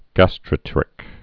(găstrə-trĭk)